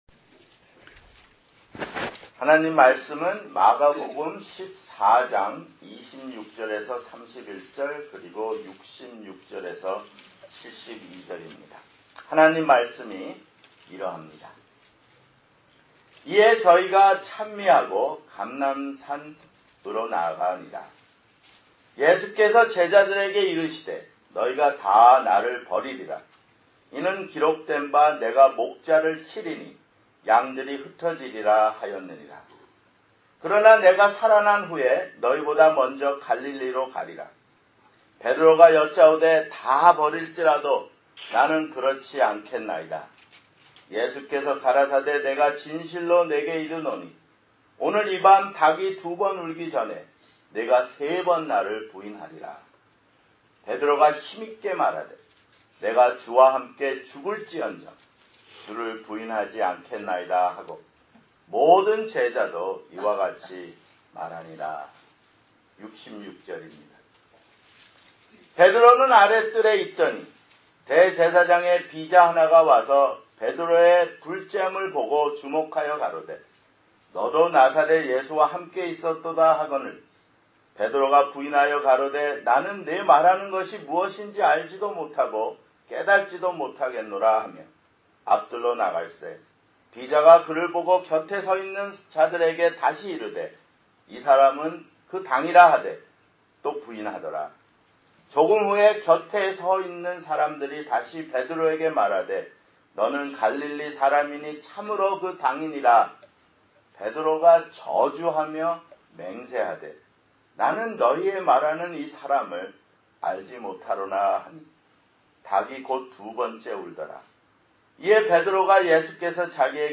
마가복음 , 주일설교